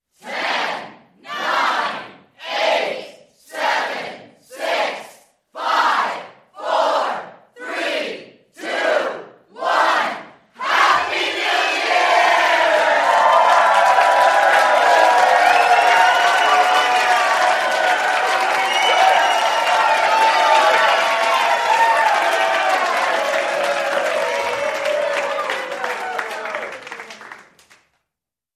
Толпа: отсчет до Нового Года на английском